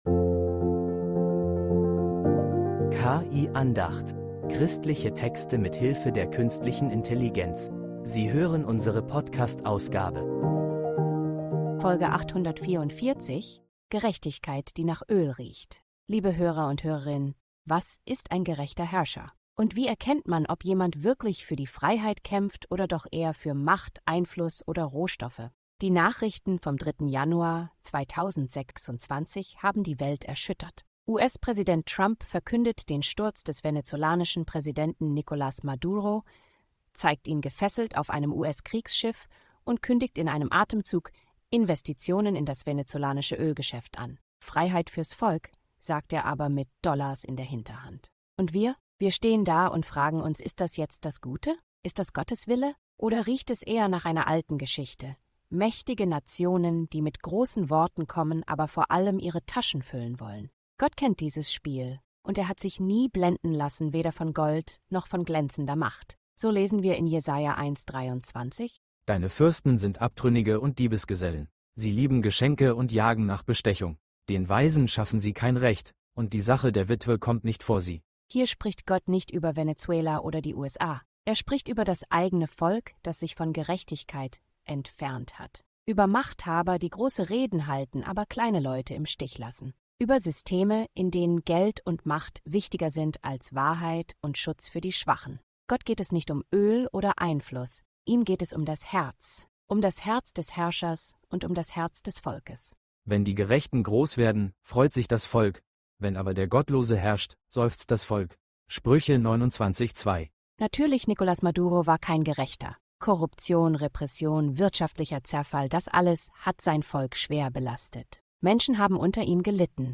Diese Andacht fragt, was echte Gerechtigkeit ist